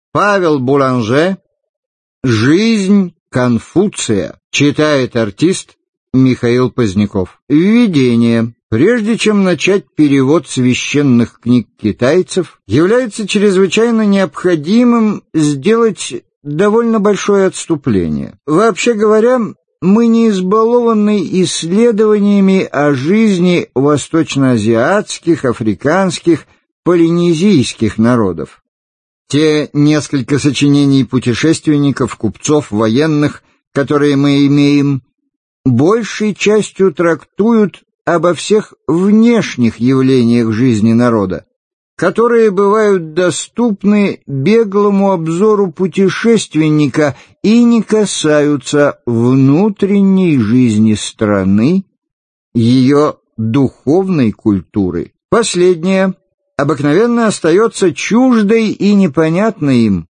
Аудиокнига Жизнь и учение Конфуция | Библиотека аудиокниг